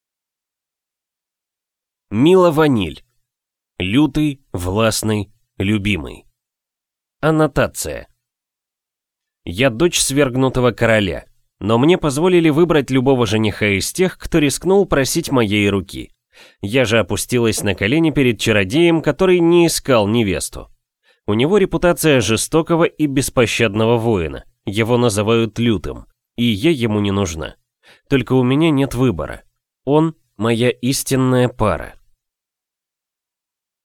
Аудиокнига Лютый, властный, любимый | Библиотека аудиокниг